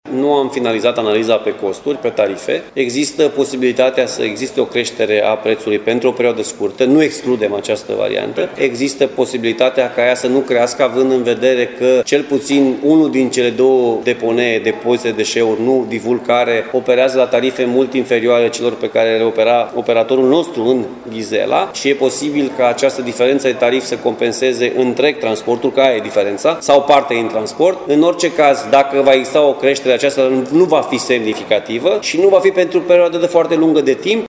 Președintele Consiliului Județean Timiș, Alfred Simonis, spune că încă nu există o decizie defintivă pe acest subiect.